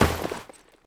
/cstrike/sound/player/footsteps/
snow1.wav